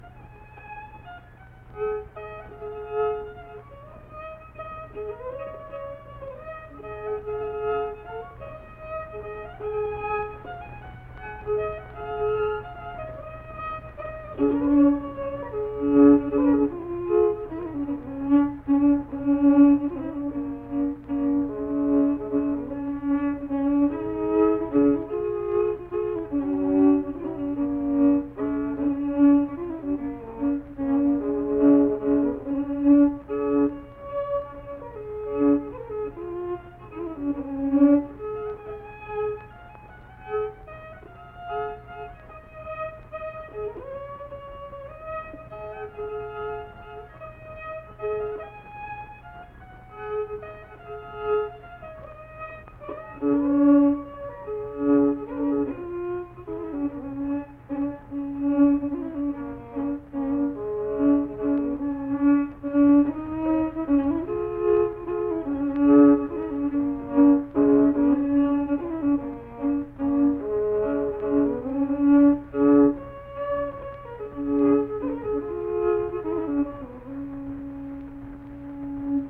Unaccompanied fiddle music
Verse-refrain 2(2).
Performed in Ziesing, Harrison County, WV.
Instrumental Music
Fiddle